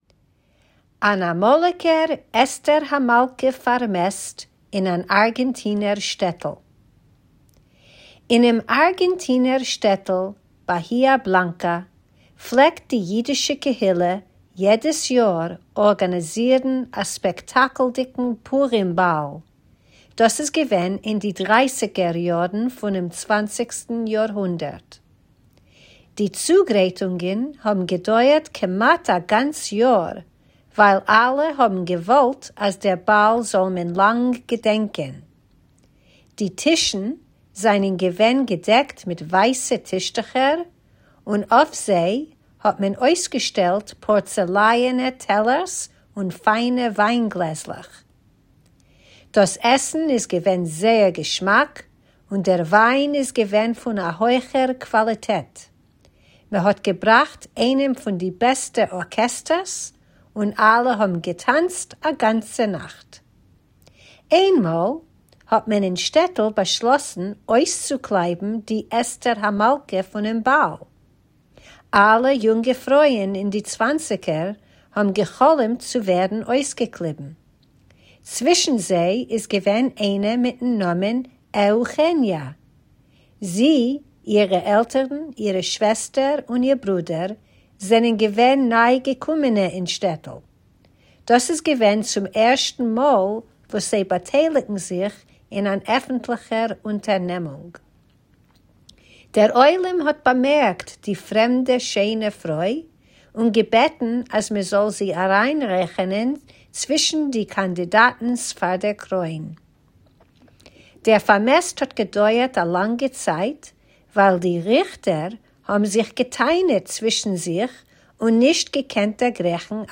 Tidbits is a Forverts feature of easy news briefs in Yiddish that you can listen to or read, or both!